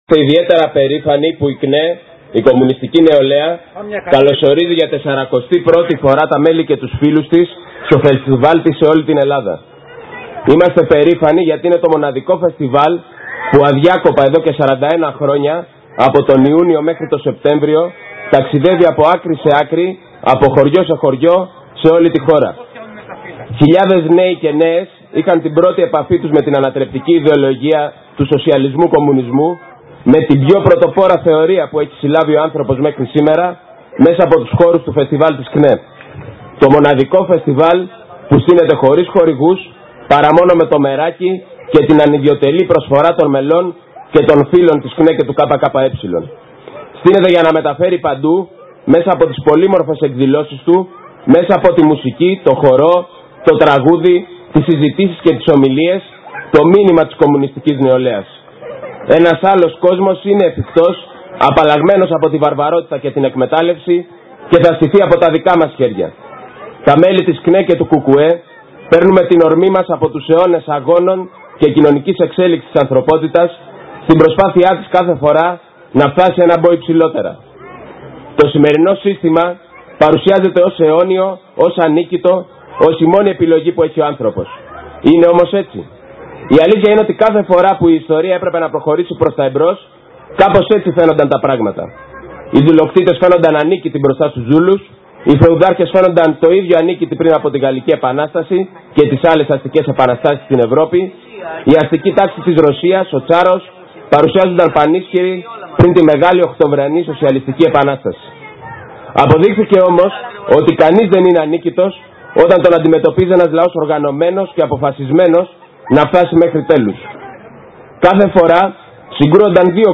Χθες ολοκληρώθηκαν στο νομό μας οι εκδηλώσεις του 41ου Φεστιβάλ της ΚΝΕ και του Οδηγητή στο Αργοστόλι Κεφαλονιάς στον κήπο του Νάπιερ.